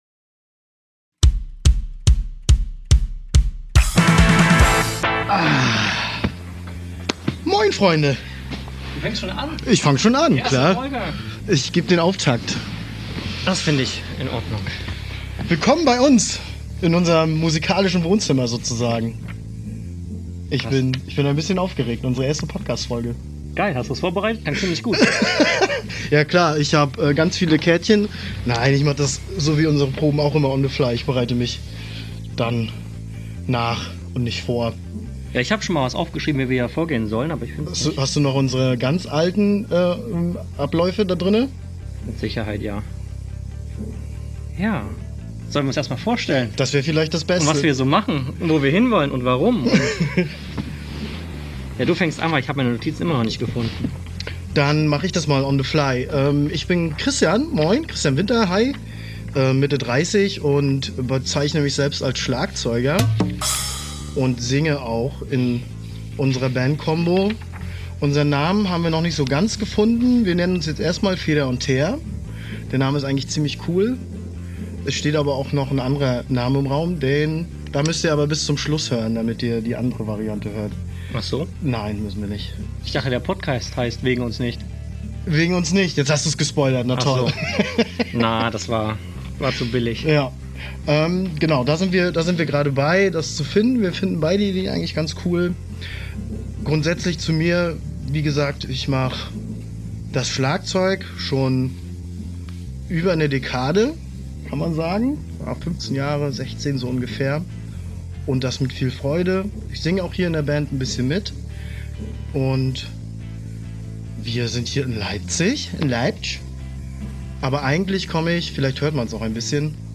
Wir erzählen wer wir sind, was wir so machen und wie es zu diesem Podcast gekommen ist. Dabei sitzen wir in unserem Proberaum und überlegen, wie unsere Band heißen soll, welche Songs wir zuerst aufnehmen und was das mit dem Podcast dabei eigentlich soll.